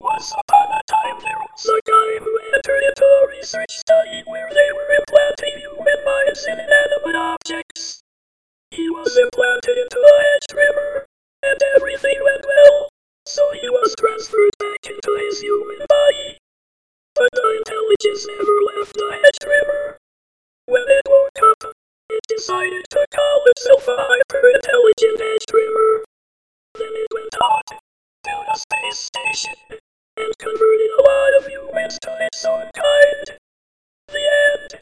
Here's a stupid voice of my own creation (no idea why the pitch isn't right) reading a stupid story I wrote about a hyper-intelligent hedge trimmer.